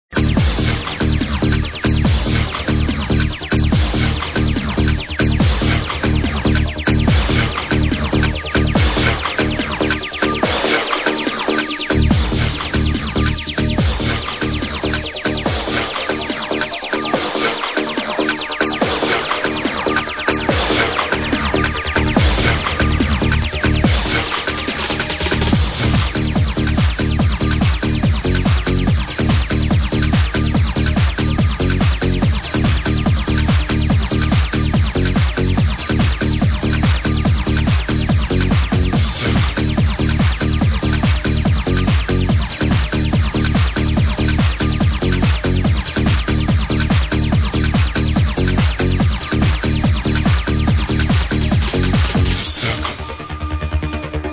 Anyway, bassline sound really similar, give it a try.